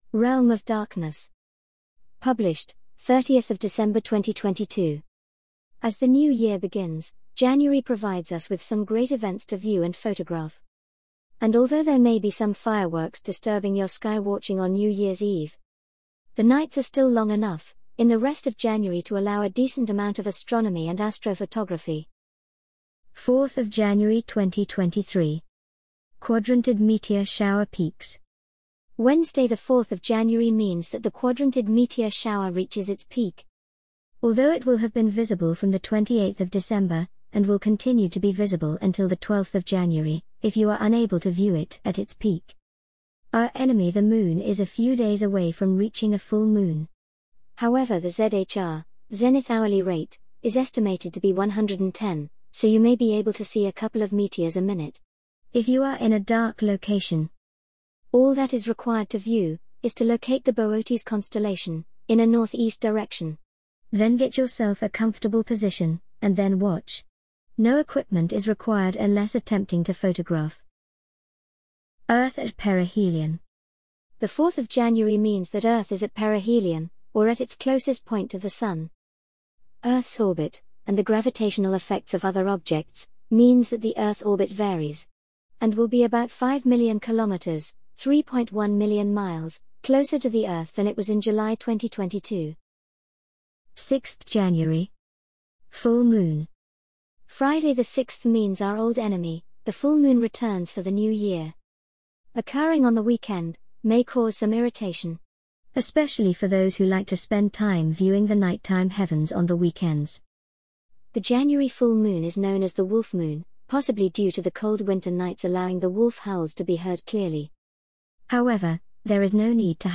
An audio reading of the Realm of Darkness January 2023 Article